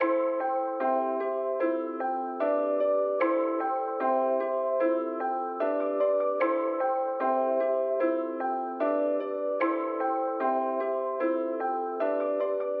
未来的 "魔法师 "类型的旋律
标签： 150 bpm Hip Hop Loops Piano Loops 2.15 MB wav Key : Unknown FL Studio
声道立体声